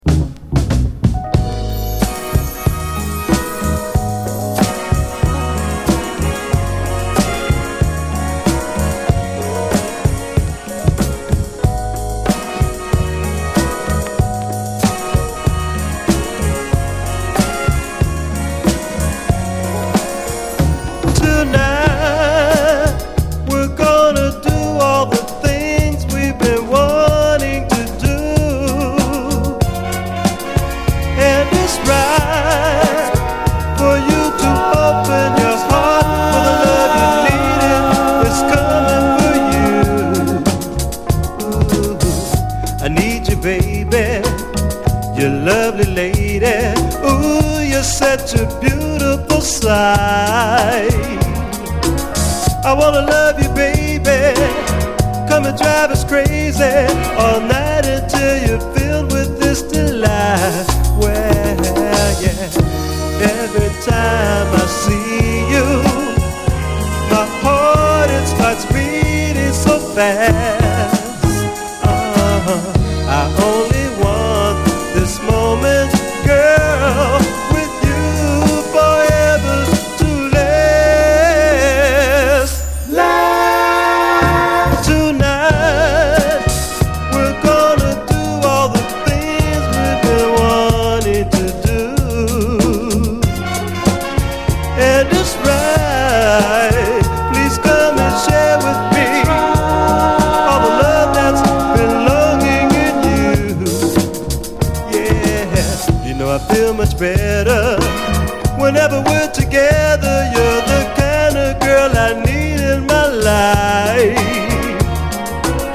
SOUL, 70's～ SOUL, LATIN, 7INCH
傑作レア・チカーノ・クロスオーヴァー・ソウル45！
イーストLAのチカーノ・ソウル・グループ
これが紛れもない、生粋のイーストLAサウンドだ！